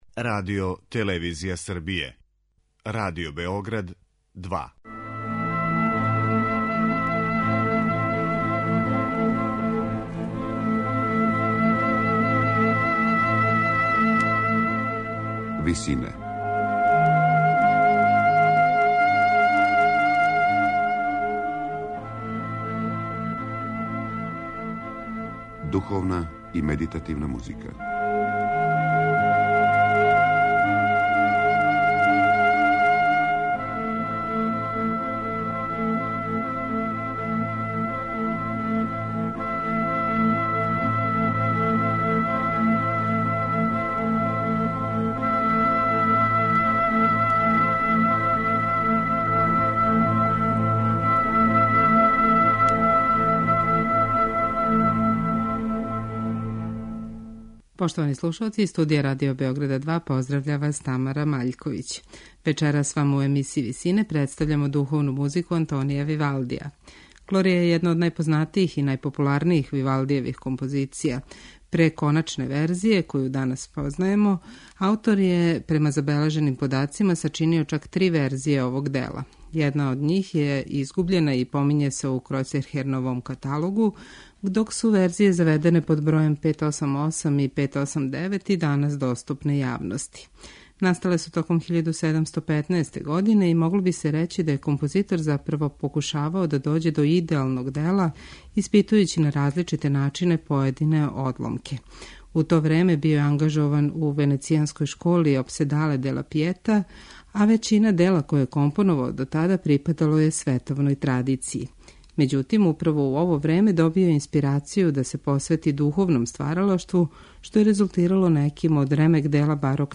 Слушаћете интерпретацију Монтеверди хора и Енглеских барокних солиста, под управом Џона Елиота Гардинера.